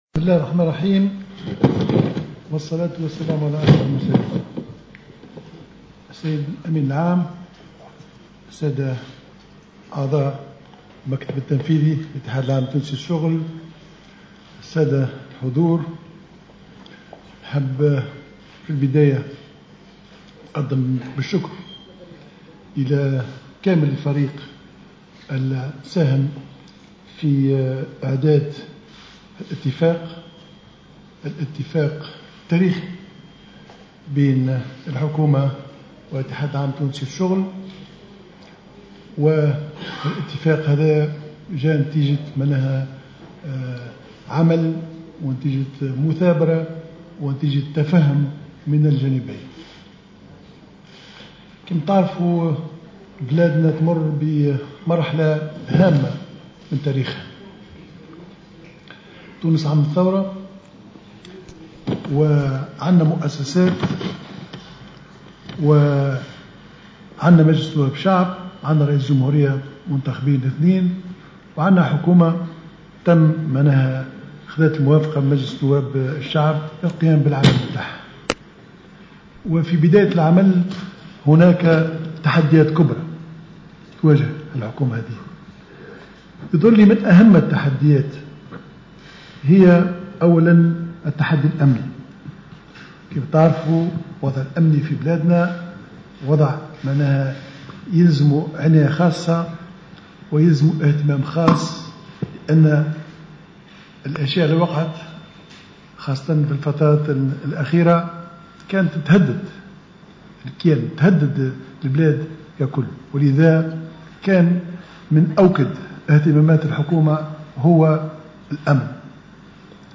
وجاء ذلك في كلمة ألقاها على هامش مراسم حفل توقيع اتفاق الزيادة في أجور العاملين في الوظيفة والقطاع العام بين الاتحاد العام التونسي للشّغل والحكومة بالقصبة وبإشراف رئيس الحكومة والأمين العام للاتحاد حسين العباسي وأكد الصيد أن الاتفاق تاريخي بين الحكومة والاتحاد وجاء نتيجة "تفهّم" بين الطرفين.